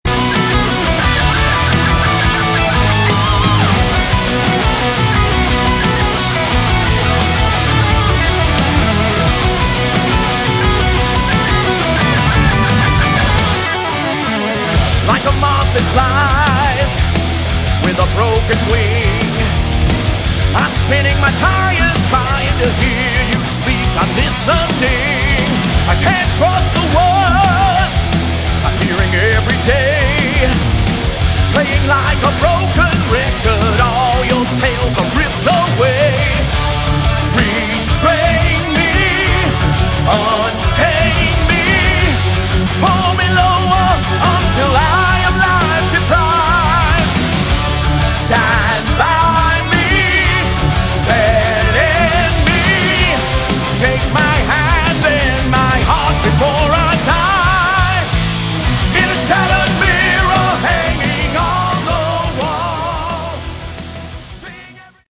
Category: Melodic Metal
vocals
guitars
bass
drums
kayboard, piano
synth and orchestral arrangements